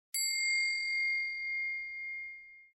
На этой странице собраны звуки, создающие ощущение абсолютной чистоты: от мягкого шума убирающегося помещения до переливов хрустальных нот.
Звон